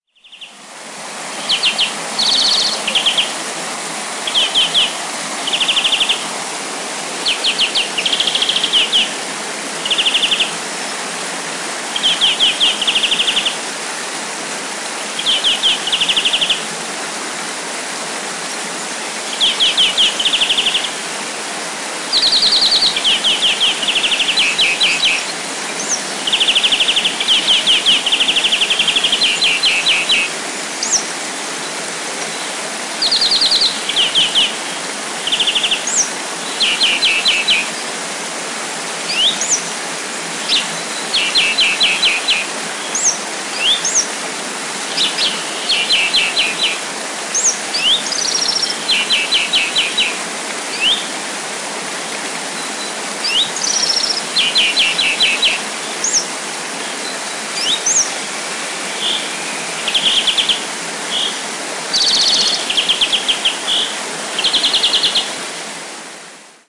Sounds Of Central London » River Thames (Water Boat)
标签： ambient fieldrecording city
声道立体声